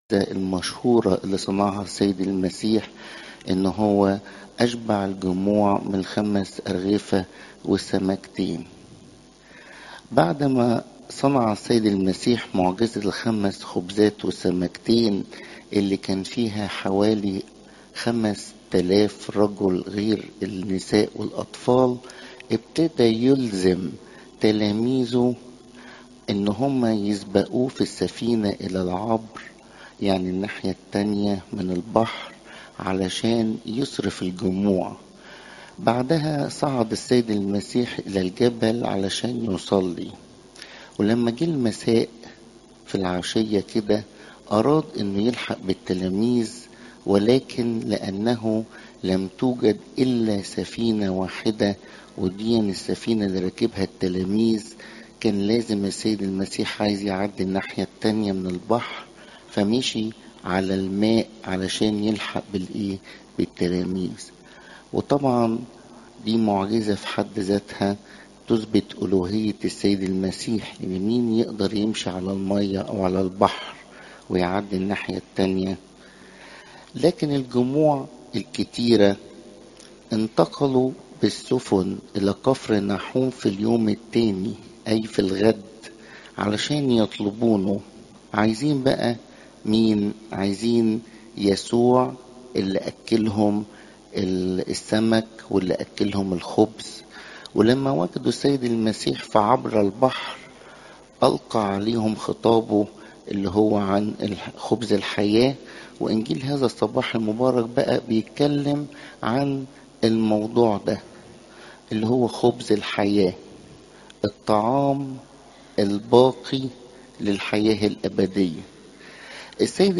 09 February 2025 19:46 Church's Holly Masses Hits